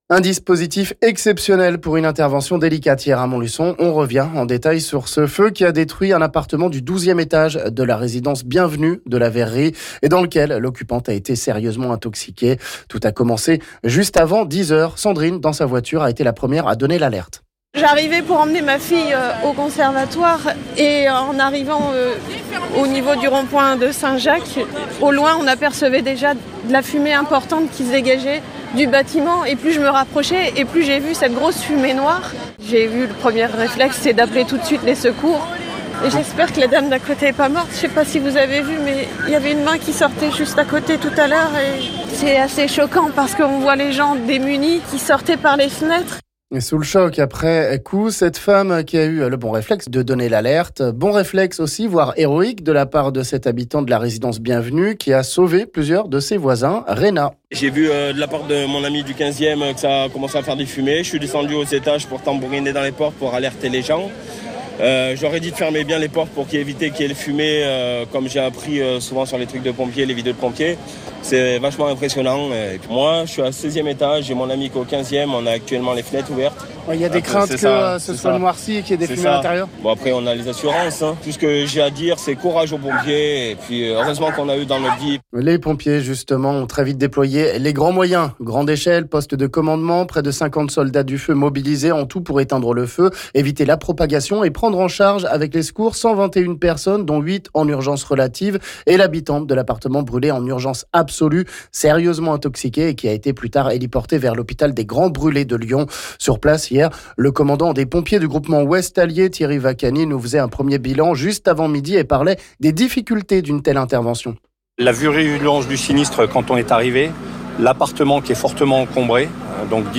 Infos Locales
Retour sur cet incendie ici avec notamment les témoignages de la première personne a avoir donné l'alerte et d'un habitant qui a fait évacuer ses voisins...